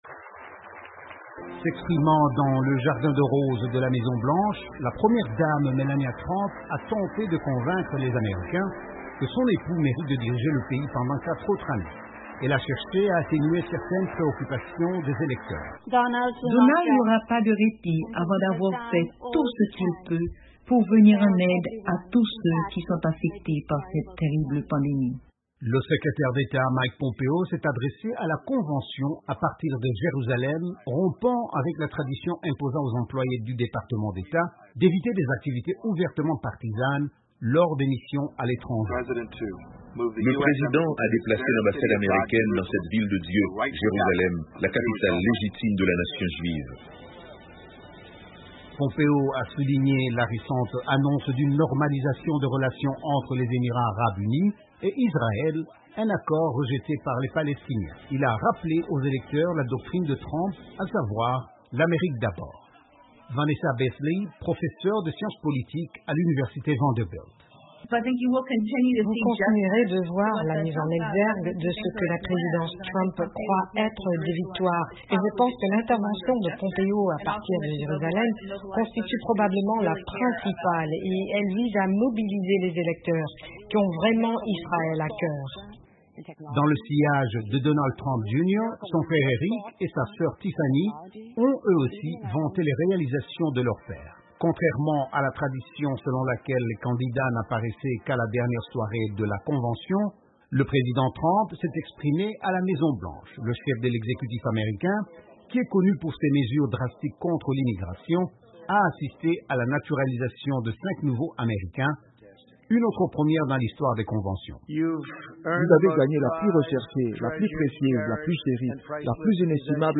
Elections américaines 2020: la première dame Melania Trump a plaidé en faveur d’un second mandat pour le président Donald Trump durant la deuxième soirée de la Convention républicaine mardi soir. Le secrétaire d’Etat Mike Pompeo a, de son côté, rompu avec la tradition en s’adressant à la convention à partir de Jérusalem.